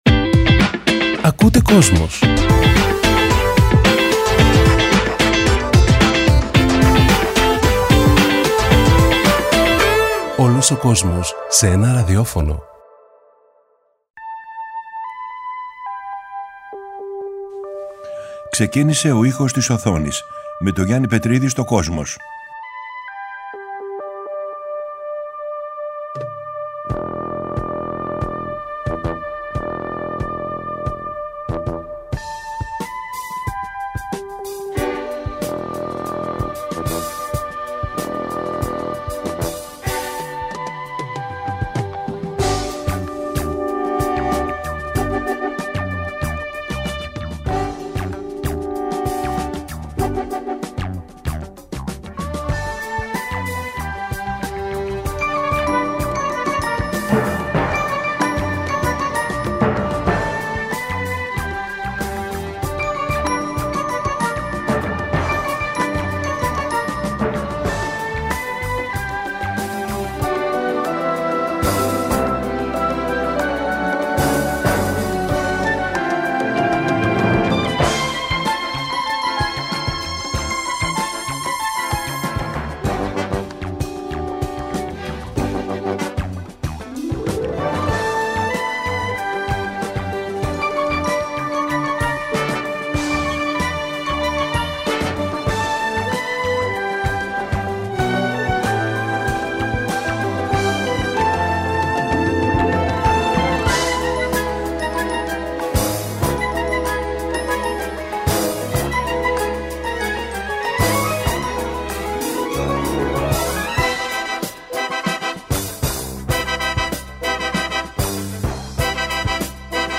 Μια βραδιά γεμάτη ατμόσφαιρα και δυνατές κινηματογραφικές στιγμές.